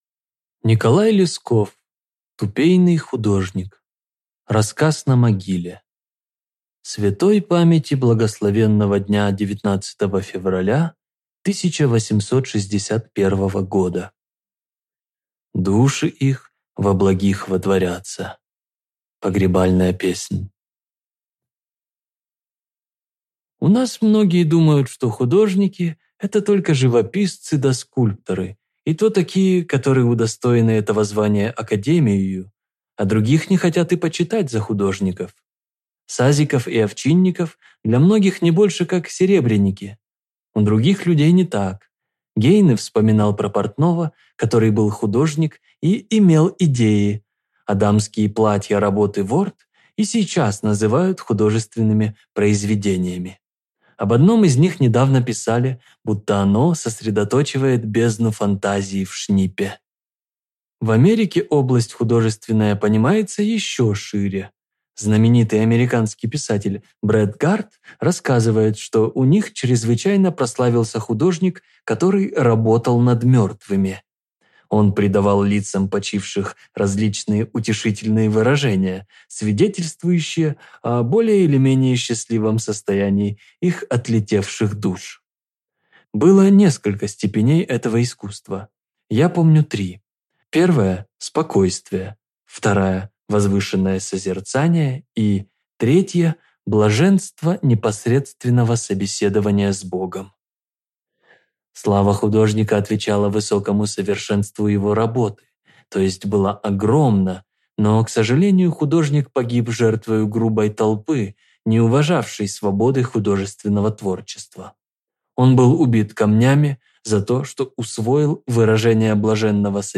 Аудиокнига Тупейный художник | Библиотека аудиокниг